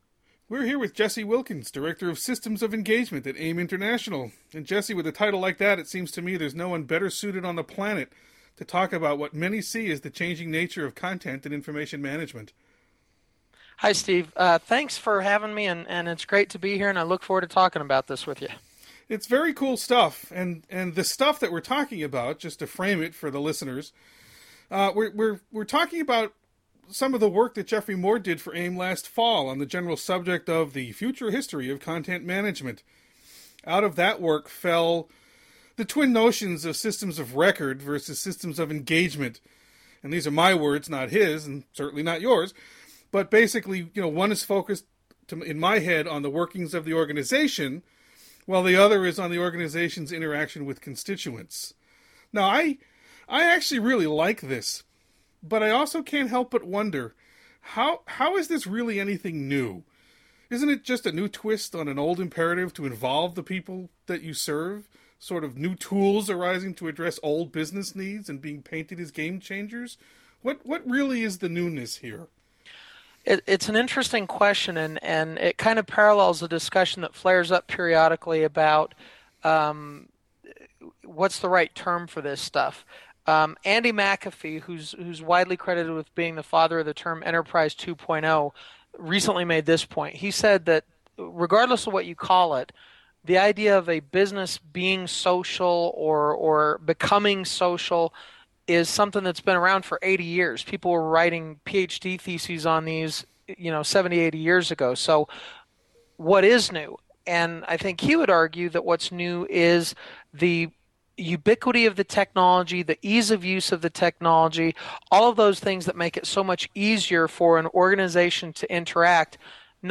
Expert Audio: What Is (and Isn’t) New About ‘Systems of Engagement’